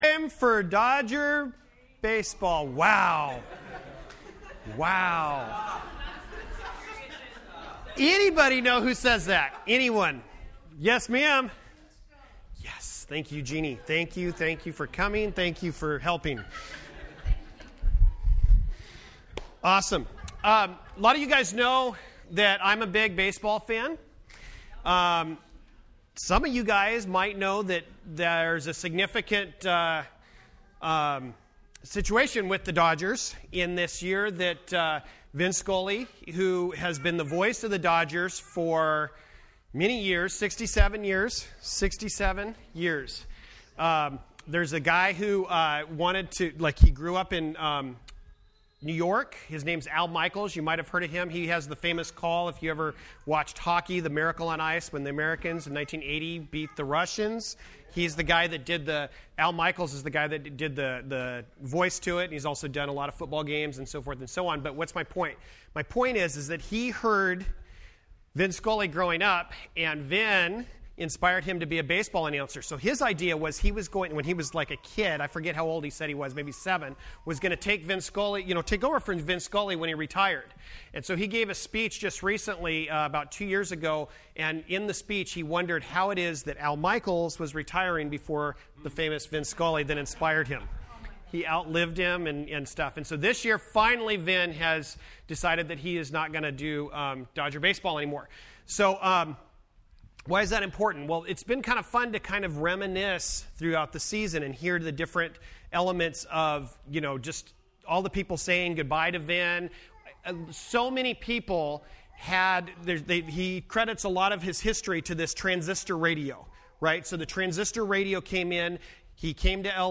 FWC Sermons Service Type: Sunday Morning %todo_render% Related « God’s Vinyl